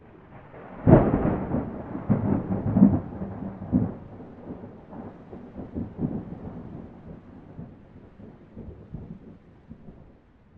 thunder.ogg